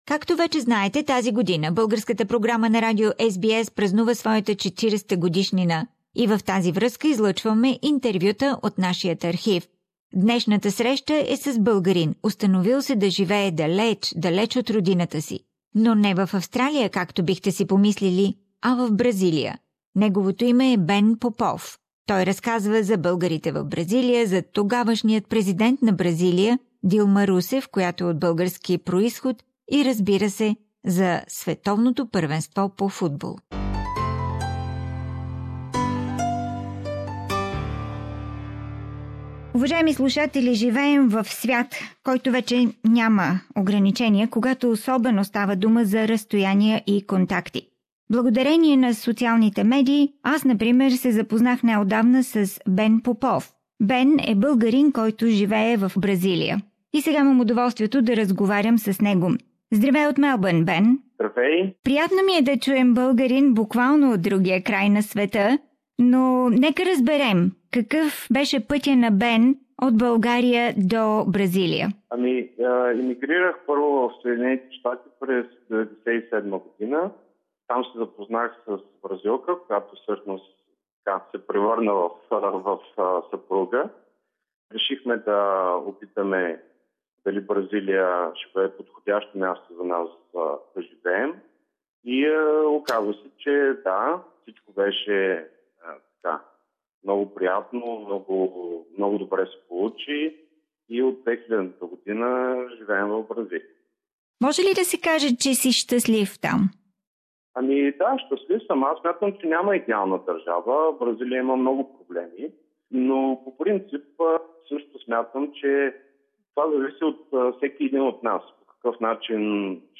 The Bulgarian program on SBS radio celebrates its 40th anniversary and we broadcast interviews from our archive.